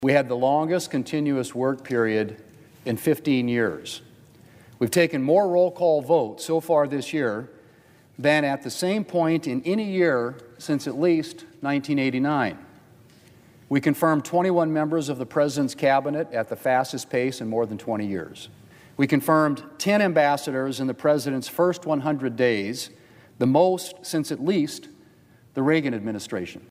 WASHINGTON, D.C.(HubCityRadio)- On Wednesday, Senate Majority Leader John Thune was on the floor of the U.S. Senate to reflect on the first six months of the session.